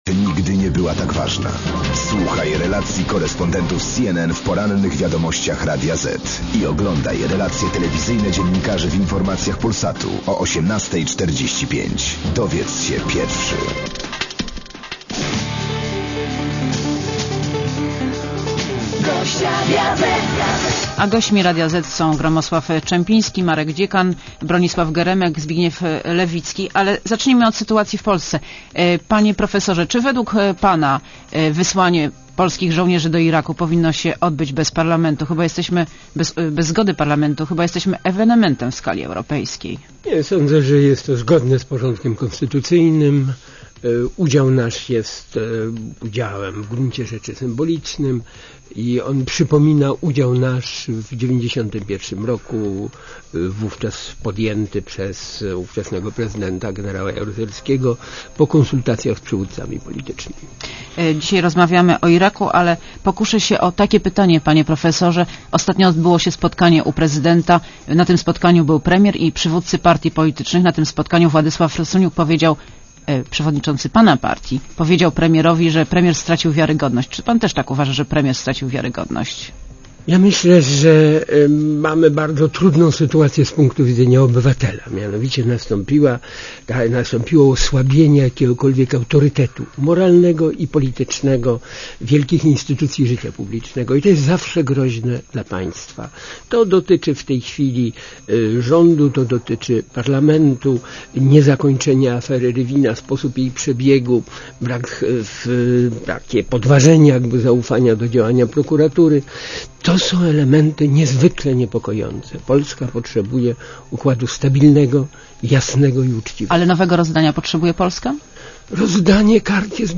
Monika Olejnik rozmawia z Bronisławem Geremkiem - byłym szefem polskiej dyplomacji, gen. Gromosławem Czempińskim